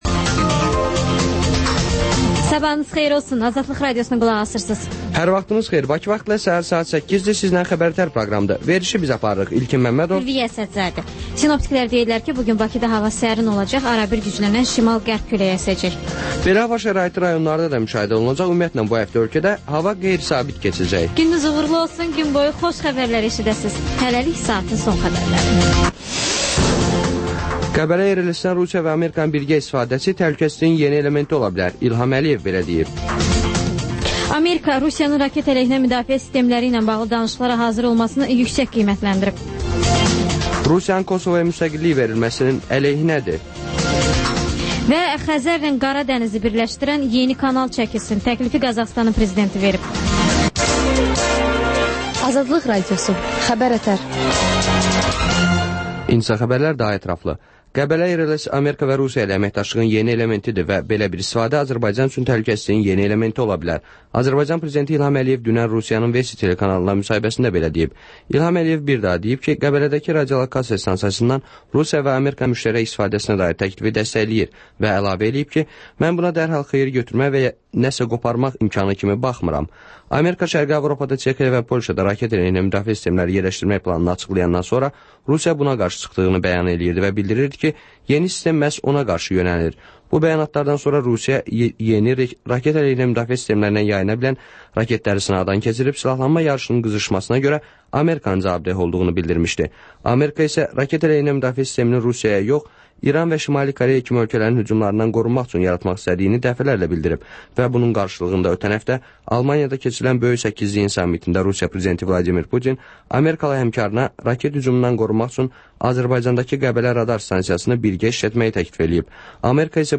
Xəbər-ətər: xəbərlər, müsahibələr və İZ: mədəniyyət proqramı